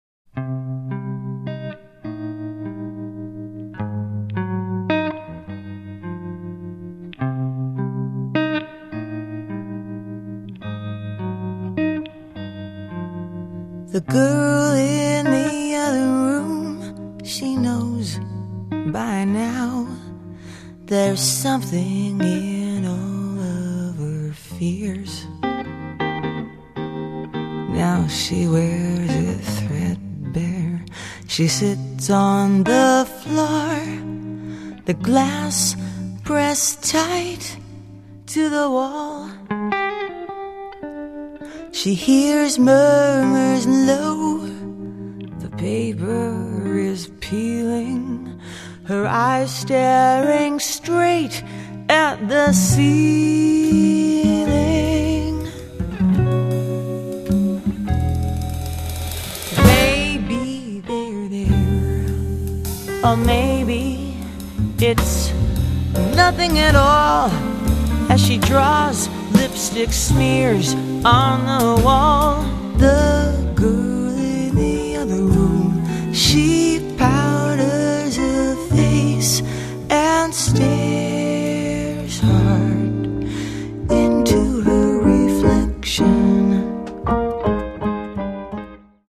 Popstar di classe, ma canta il jazz.
sa di jazz